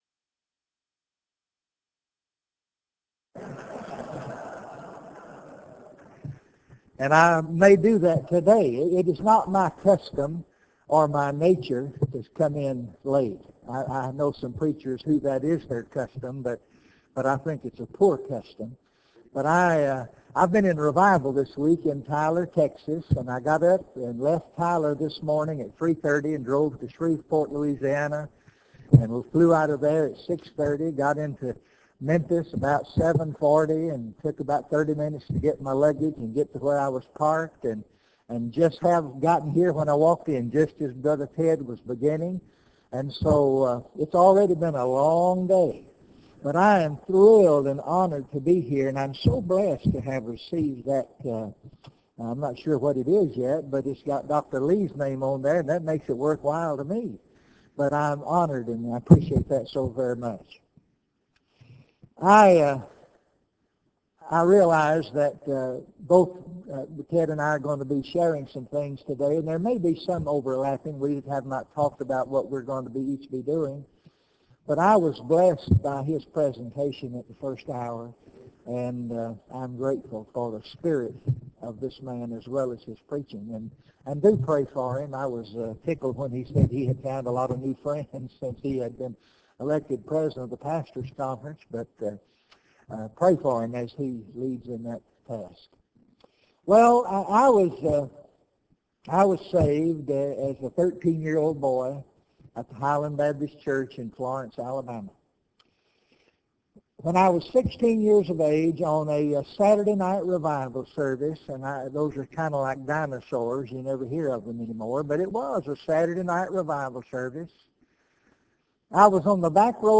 Address: "The Essence of Preaching" Recording Date: Oct 9, 2003, 10:30 a.m. Length: 47:44 Format(s): WindowsMedia Audio ; RealAudio ;